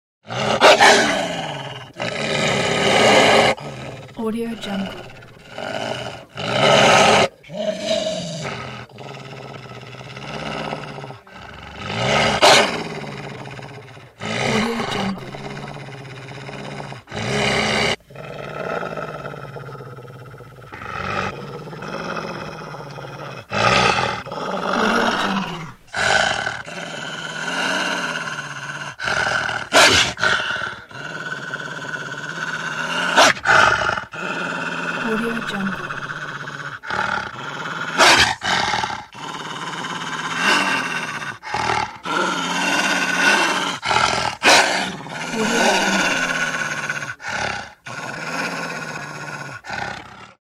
Growls Wolf Téléchargement d'Effet Sonore
Growls Wolf Bouton sonore